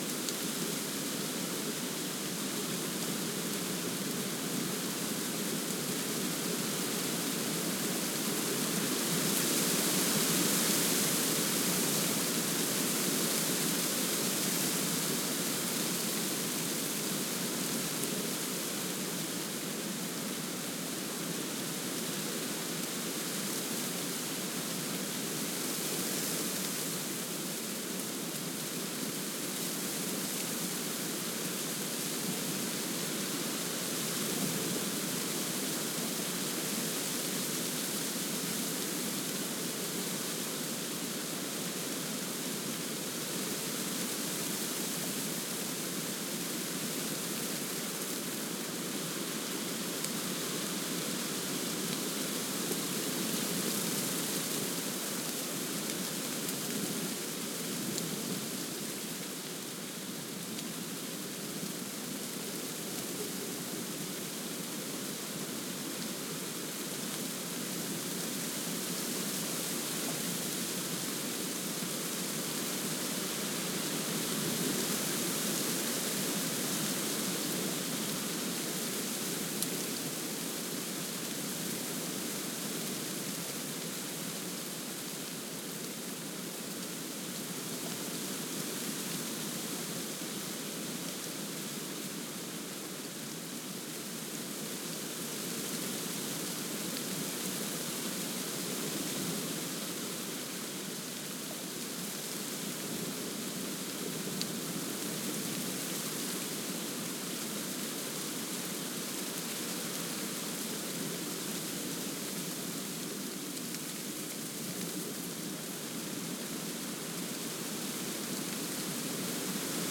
SW008_Wendland_Autumn_Wind_Storm_Inside_Undergrowth_Bush.ogg